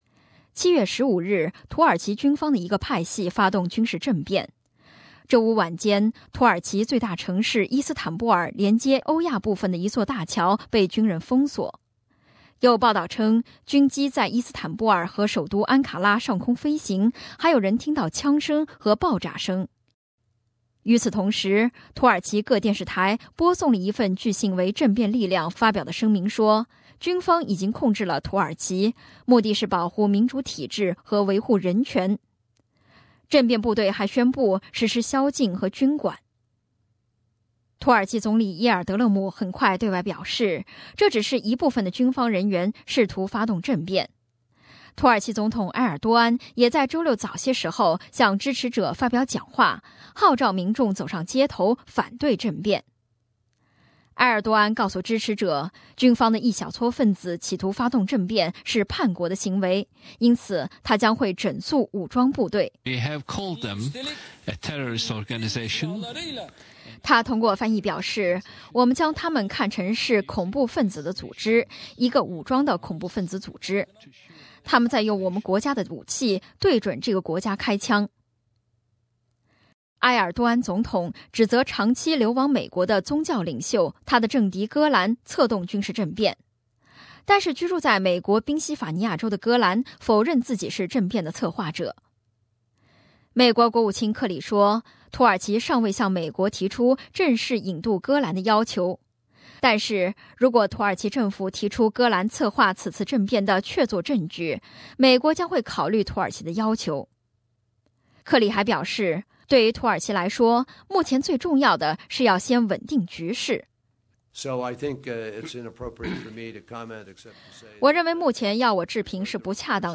土耳其军事政变未遂事件综合报道